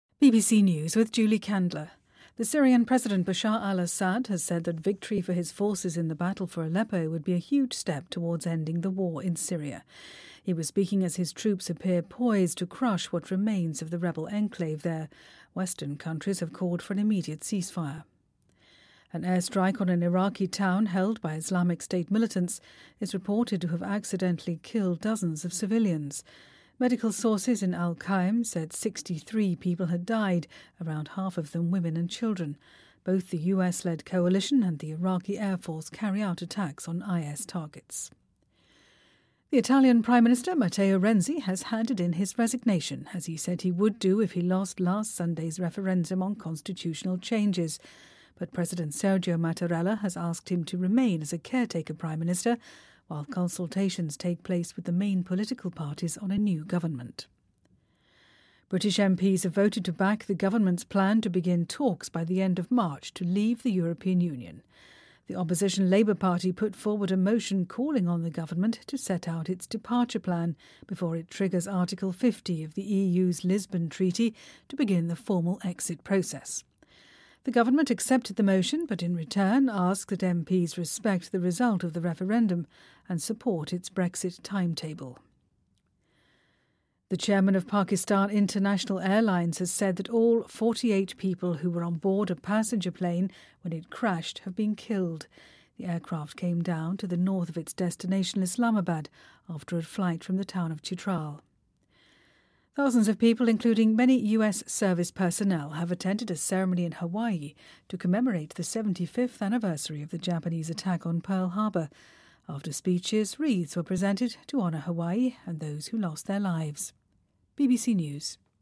BBC news,意大利总理伦齐递交辞呈